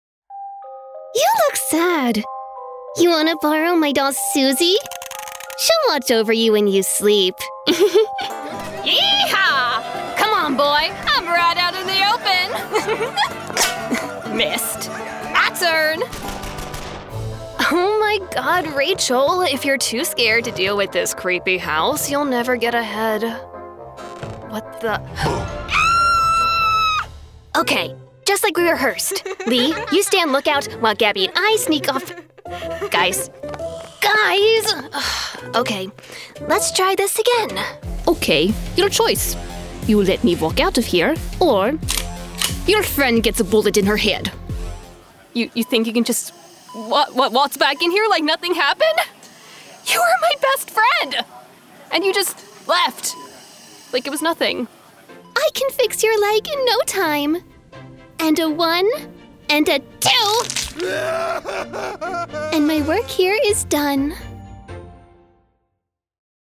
Remote Voice Actor
Animation Demo
Standard American, Southern American, Country American, British, Russian
Young Adult